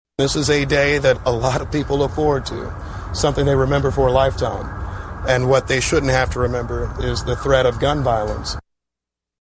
Some details were released during a news conference Thursday morning.
0502-lucas-people-shouldnt-have-to-worry.mp3